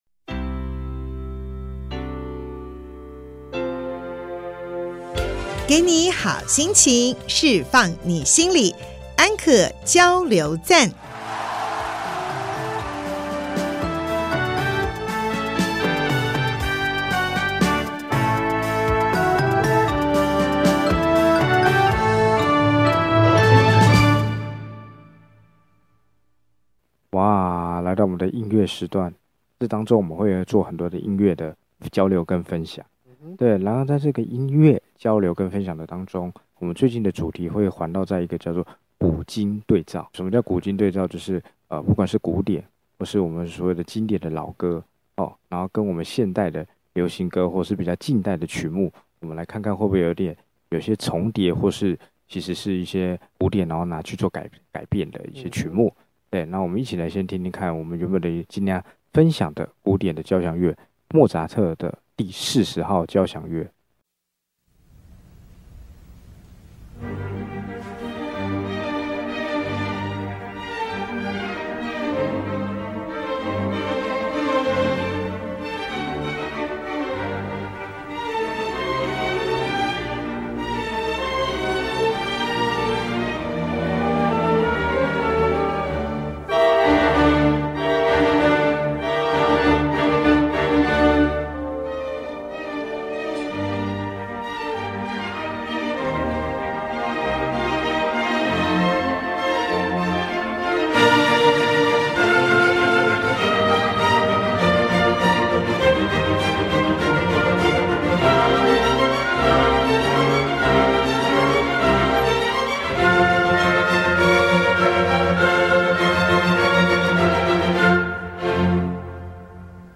節目裡有生活點滴的分享、各界專業人物的心靈層面探析及人物專訪